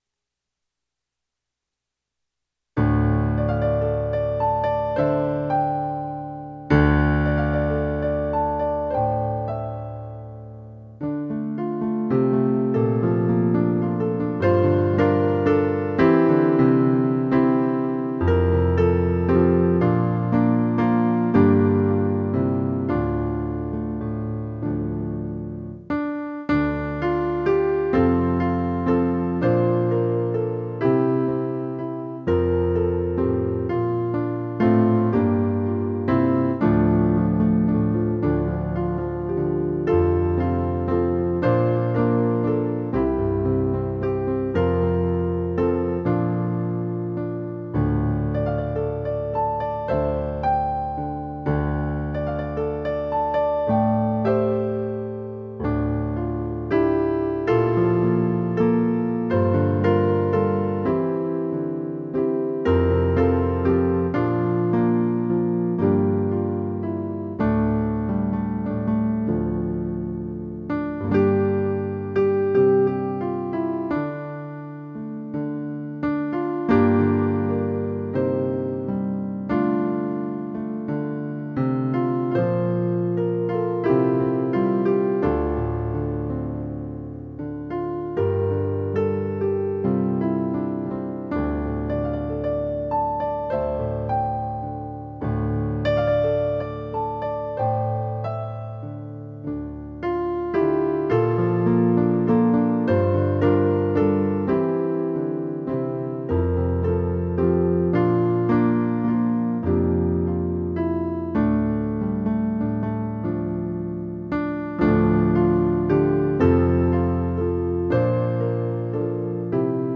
Create In Me - Full Accompaniment